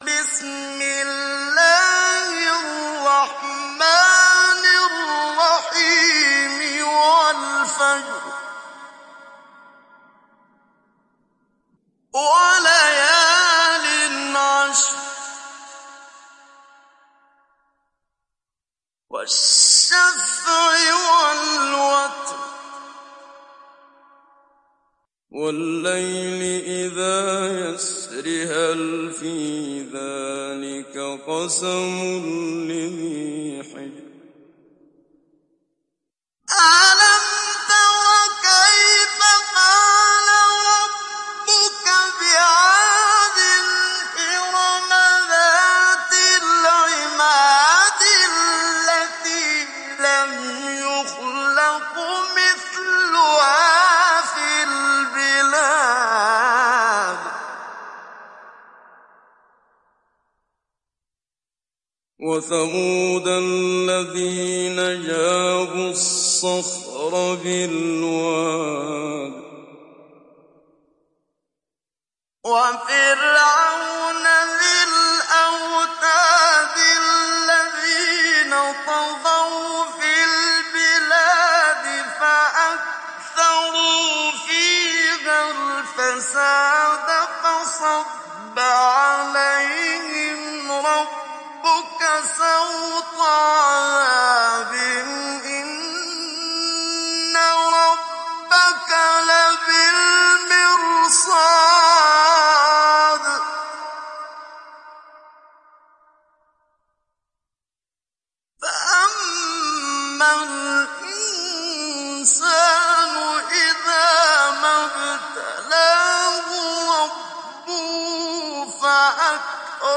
Sourate Al Fajr Télécharger mp3 Muhammad Siddiq Minshawi Mujawwad Riwayat Hafs an Assim, Téléchargez le Coran et écoutez les liens directs complets mp3
Télécharger Sourate Al Fajr Muhammad Siddiq Minshawi Mujawwad